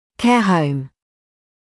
[keə həum][кэа хоум]учреждение с приживанием для уходя за людьми с определенными потребностями